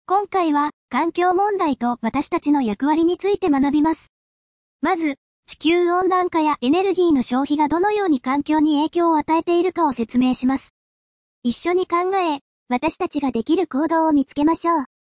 AI音声合成・音声読み上げ（WEB テキスト）ソフトのReadSpeaker（リードスピーカー）